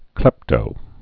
(klĕptō)